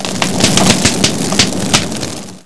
1 channel
burnhand.wav